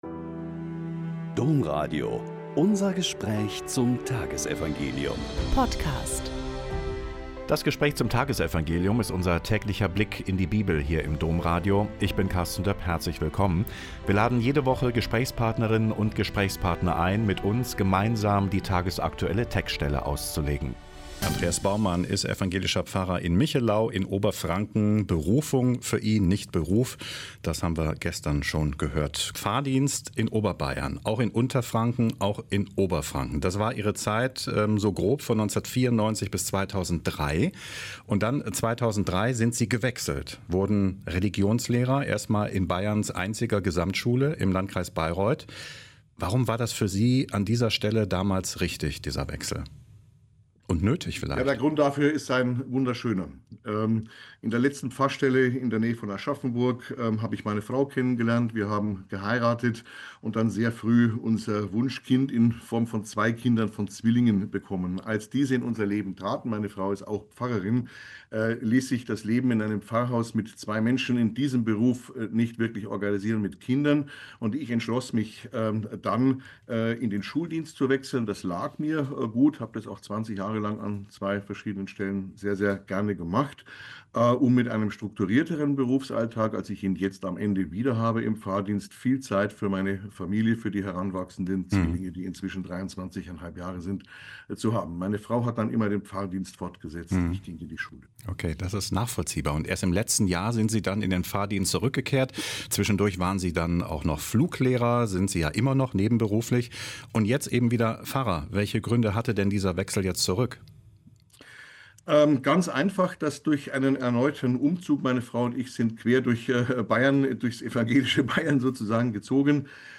Lk 11,1-4 - Gespräch